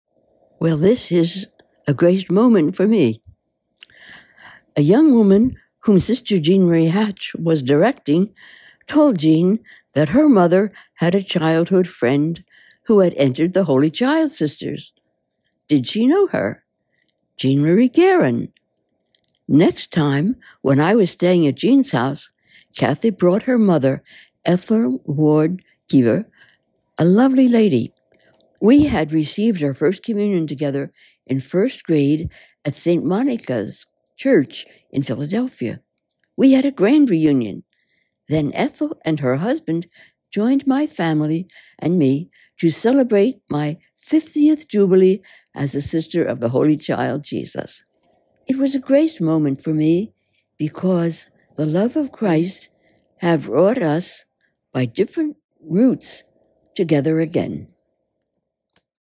In October 2024, the American Province launched Graced Moments, where every month, a Holy Child Sister shares a “grace-filled” experience that has influenced her life.